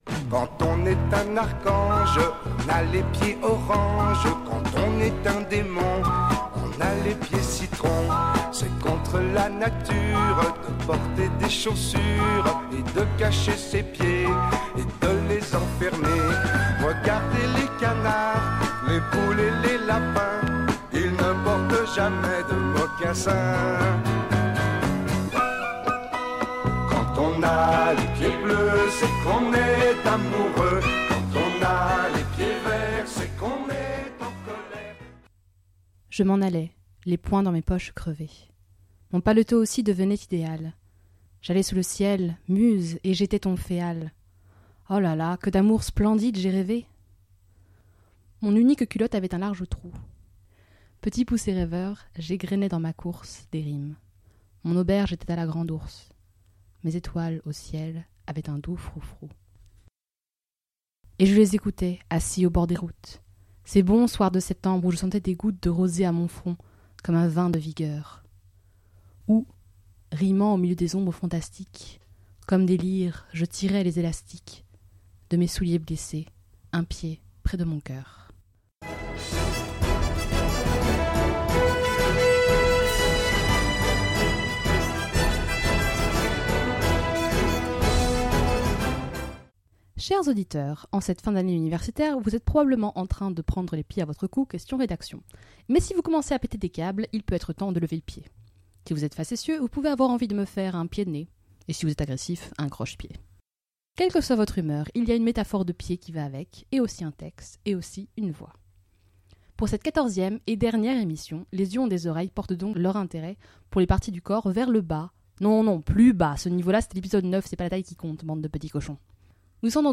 Lectures à voix haute, étonnantes et enjouées.